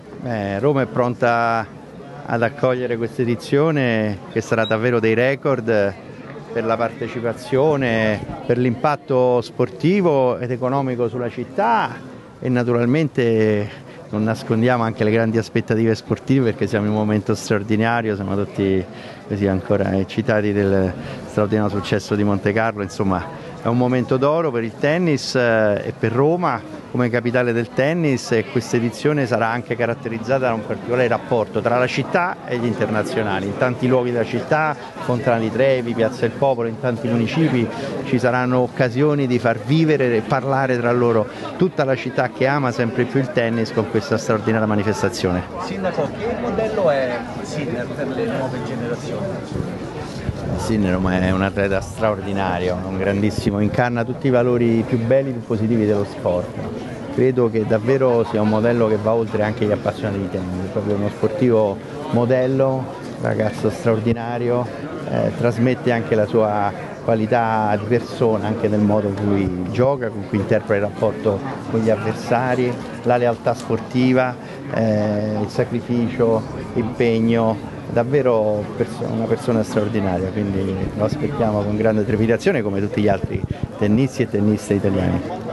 sindaco-gualtieri.mp3